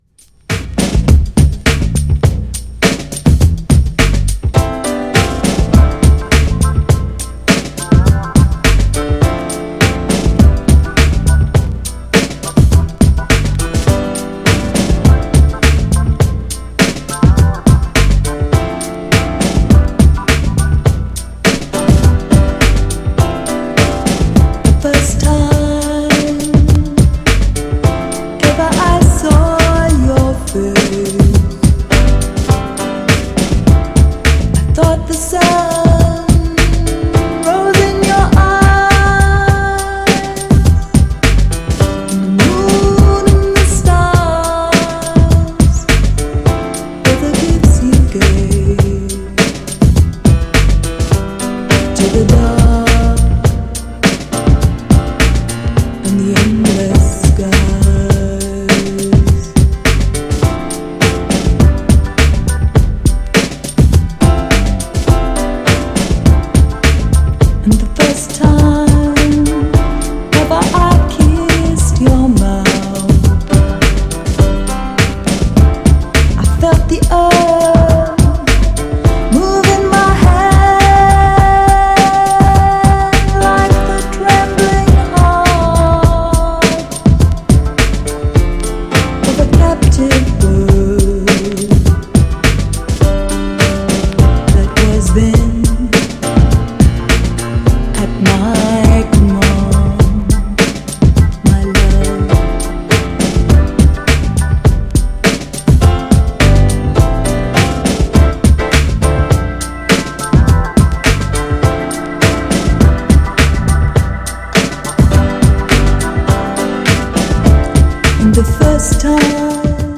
テンポ遅めのソウルのあるグルービーなグランドビート＆HOUSE!! ハモンドオルガンの音色が泣かせます!!
GENRE House
BPM 101〜105BPM